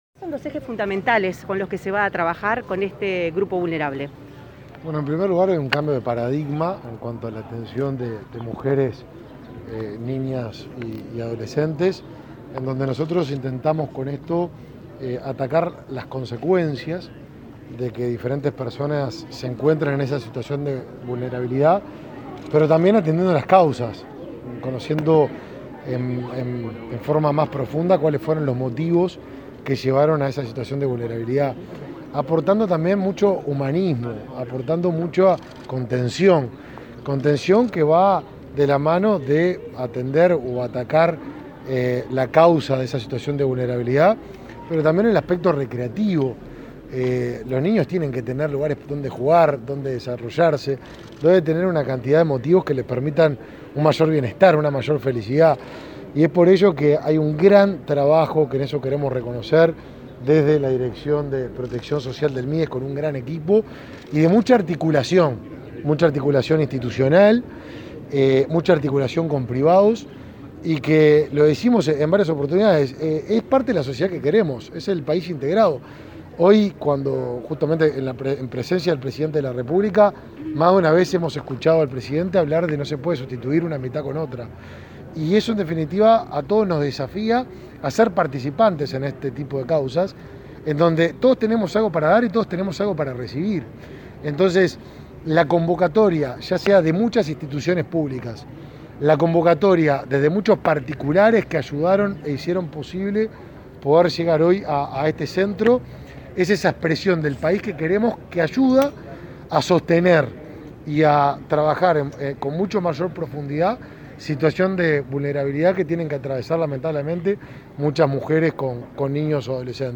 Declaraciones del ministro de Desarrollo Social, Martín Lema
En declaraciones a la prensa, el ministro de Desarrollo Social, Martín Lema, informó sobre las características del centro El Hornero, para mujeres,